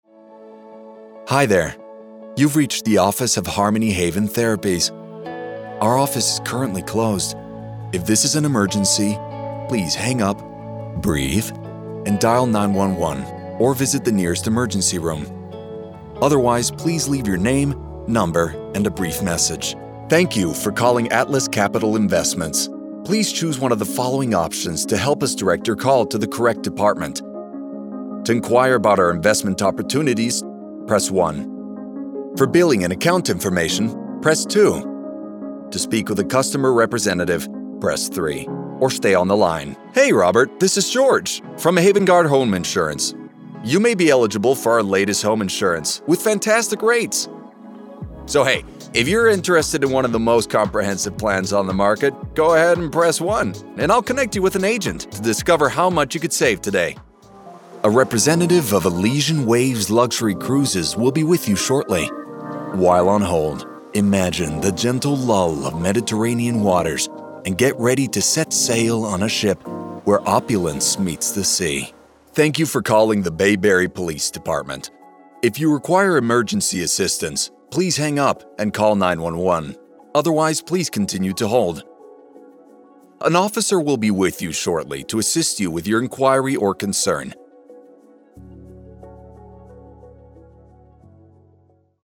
IVR & Telephony Reel
General American, Spanish, Southern USA, New Orleans
Young Adult